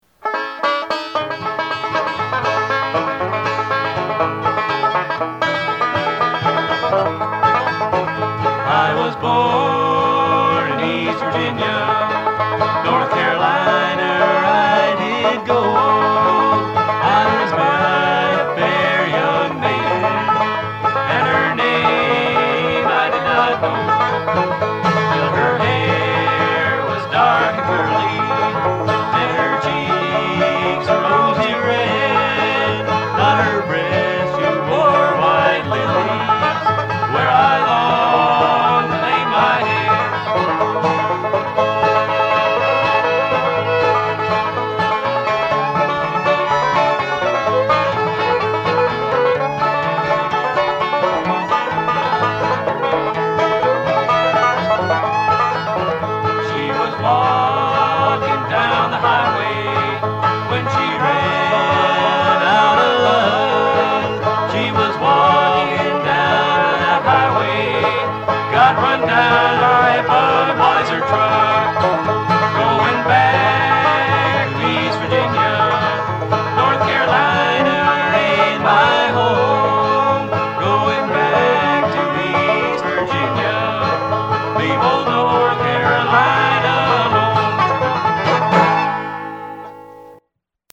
Banjo and Vocal
Guitar and Vocal
Violin
Mandolin